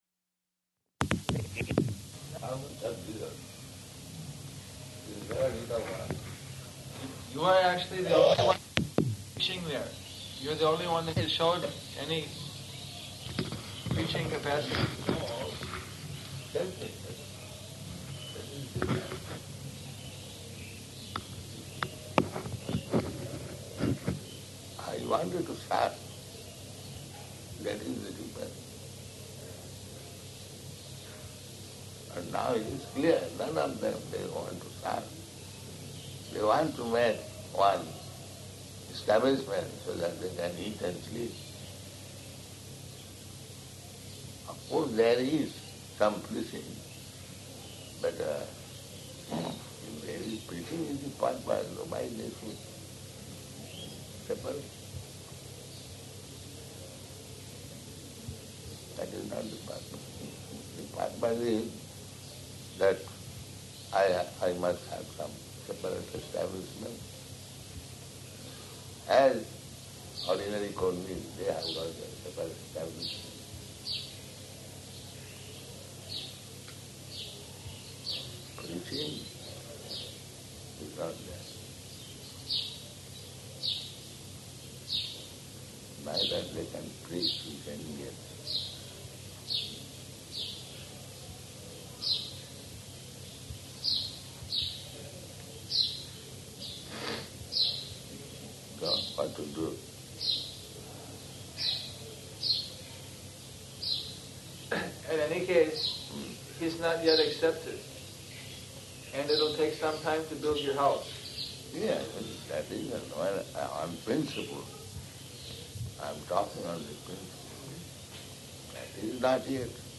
Room Conversation
Room Conversation --:-- --:-- Type: Conversation Dated: February 11th 1977 Location: Māyāpur Audio file: 770211R1.MAY.mp3 Prabhupāda: ...how such gṛhastha.